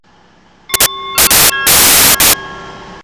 Tannoy Chime
CHIMETANNOY.wav